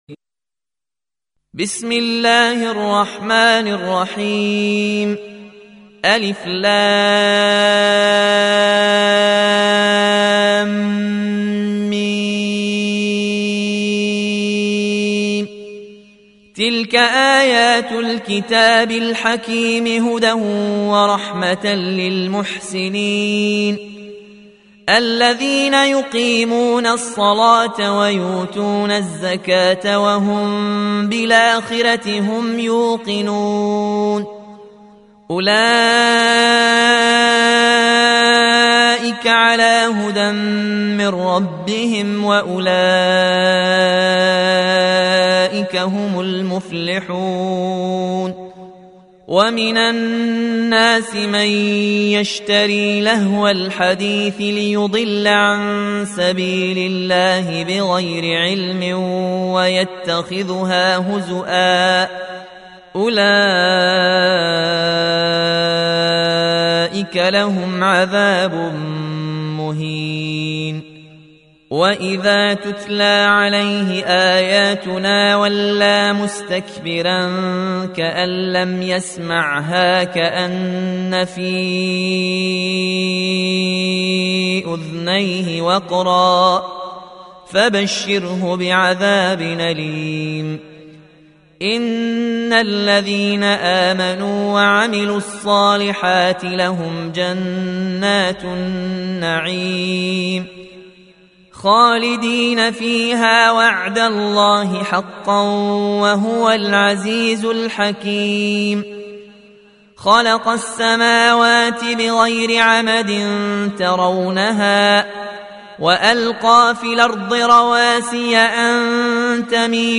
EsinIslam Audio Quran Recitations Tajweed, Tarteel And Taaleem.